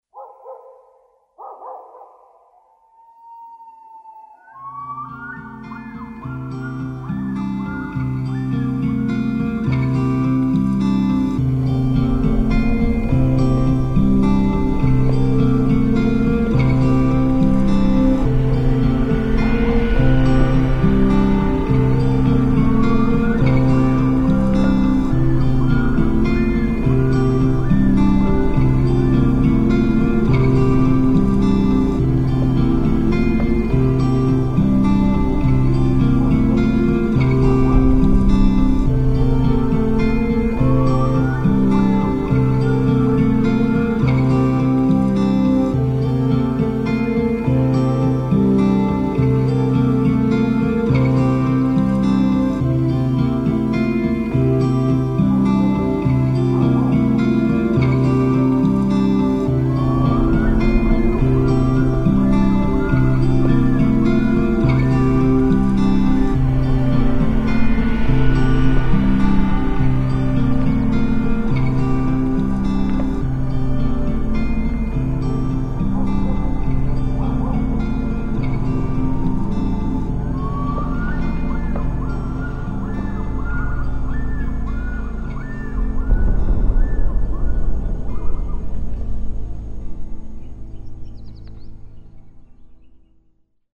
Git Loop
Dog+Bird Ambo